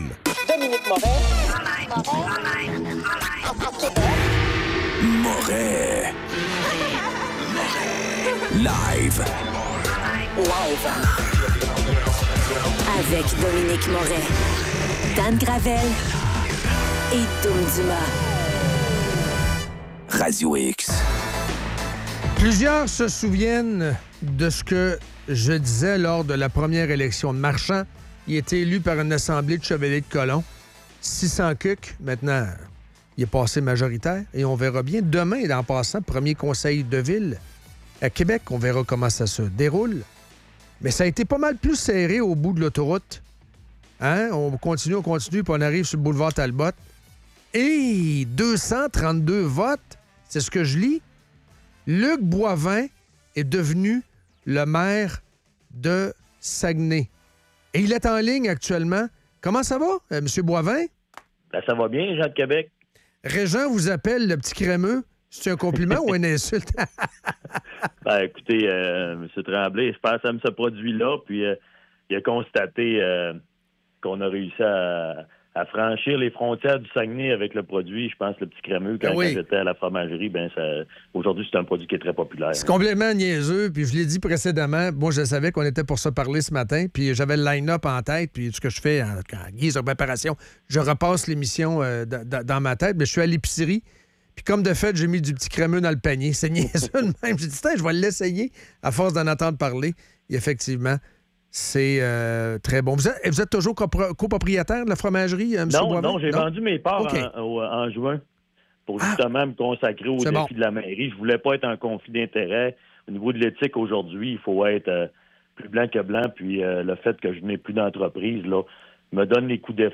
Entrevue avec Luc Boivin maire de Saguenay